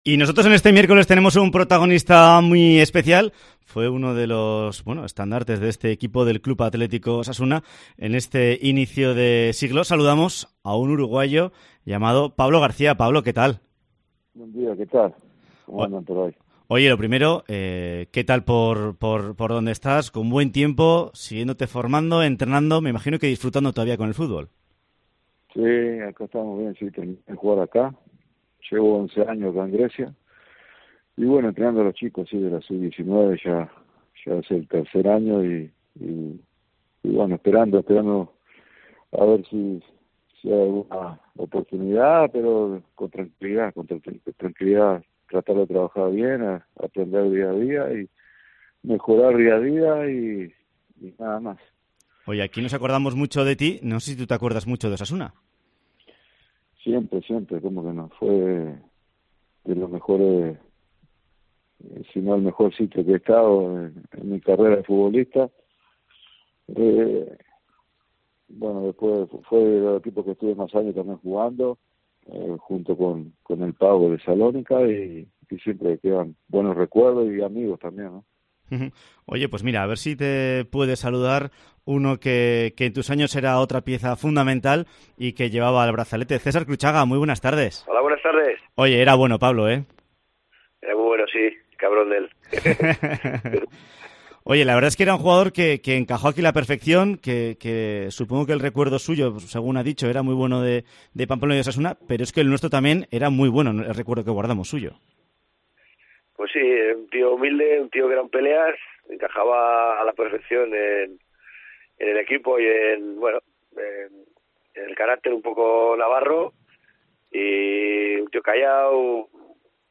Dos ex compañeros suyos como César Cruchaga y Patxi Puñal participan en la entrevista a la víspera de que se dispute el Osasuna -Real Madrid .